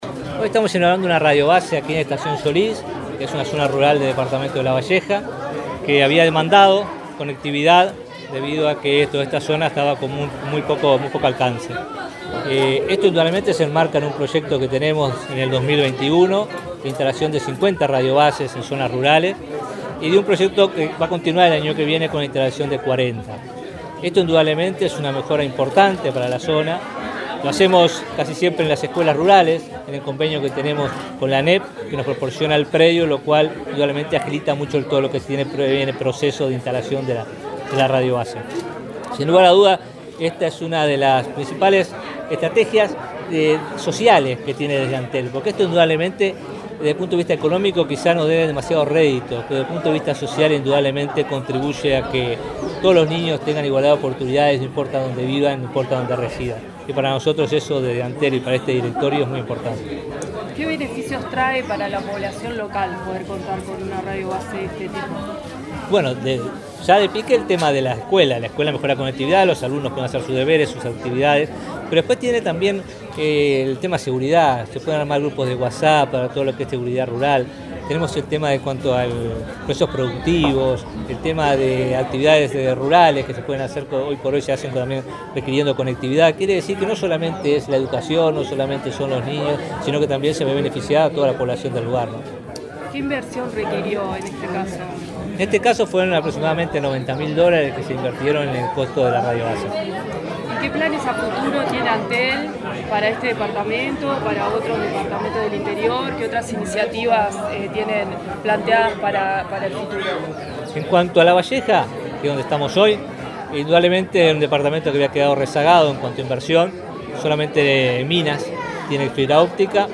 Entrevista al vicepresidente de Antel, Robert Bouvier